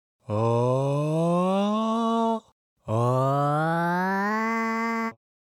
Here is a second example, with a portamento (a note that slides to another note) performed vocally, then with a pitch shifting tool. Listen to how quickly the sound becomes artificial in the second case.
Pitch-formant-comparaison-portamento.mp3